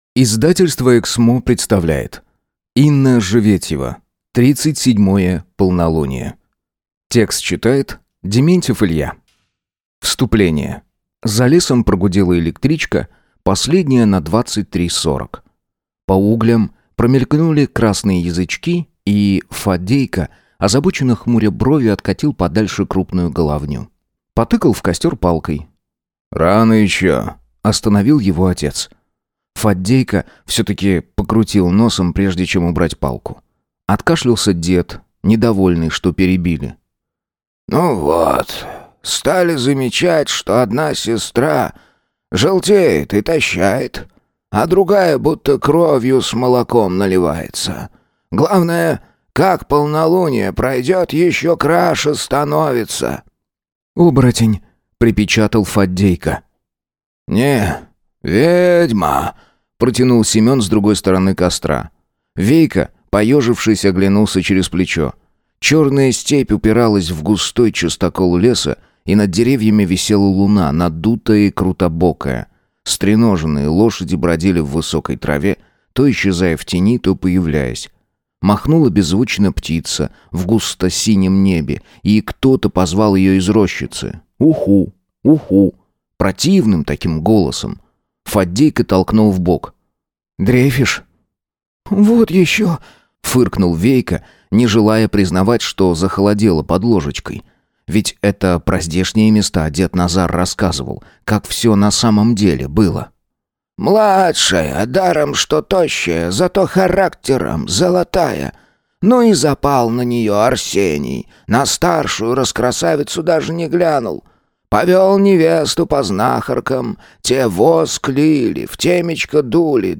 Аудиокнига Тридцать седьмое полнолуние | Библиотека аудиокниг